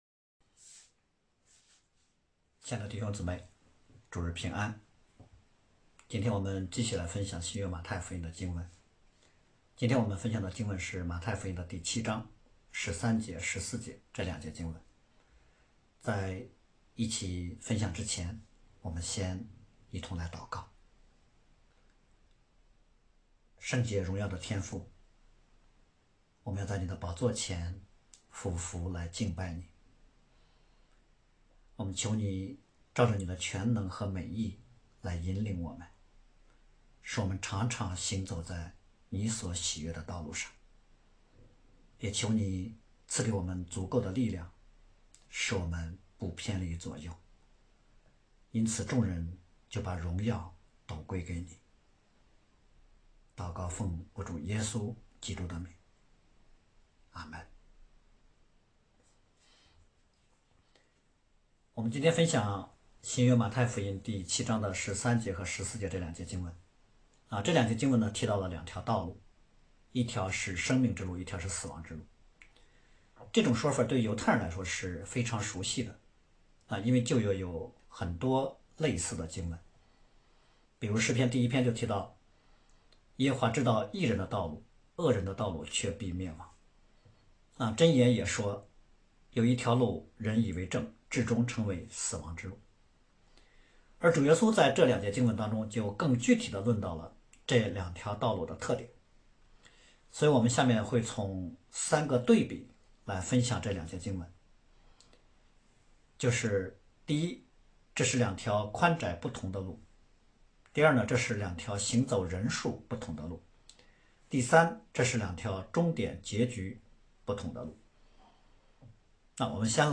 你们要进窄门——2024年12月15日主日讲章